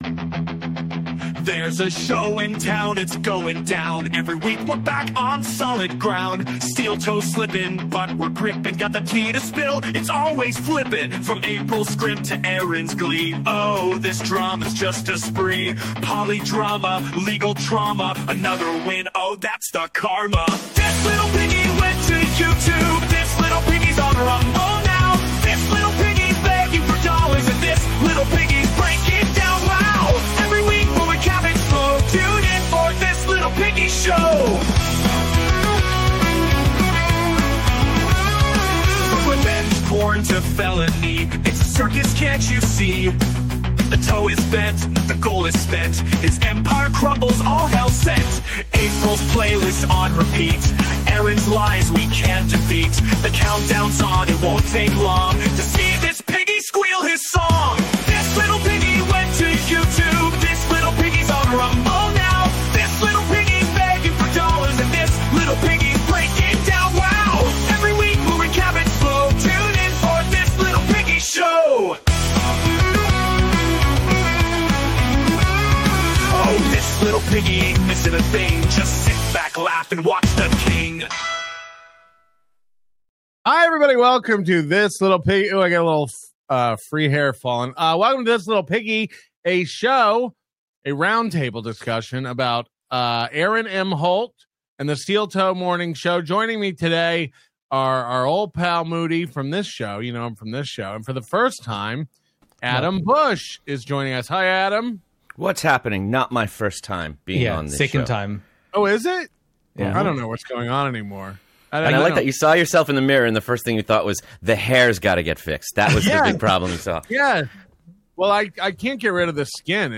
THIS LITTLE PIGGY: A Steel Toe Roundtable Discussion (April 4, 2025) • NOBODY LIKES ONIONS